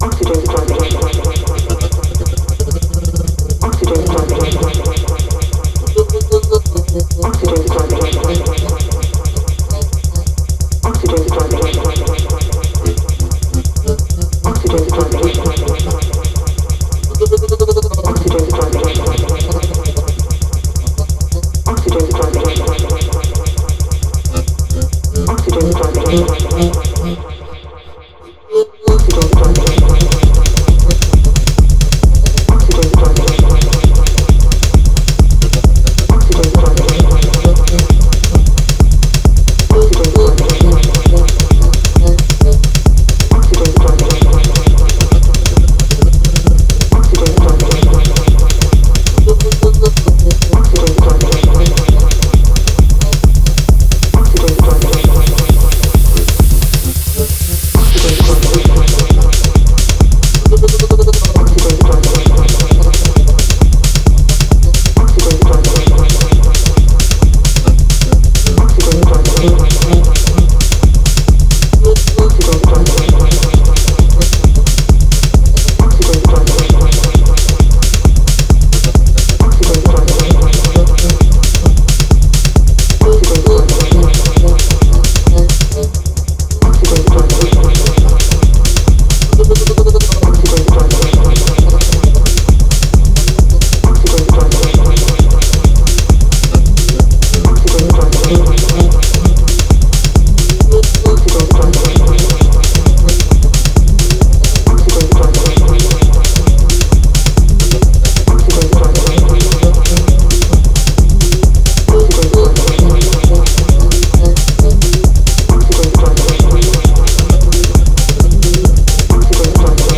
Genre: Tech House, Techno, Minimal.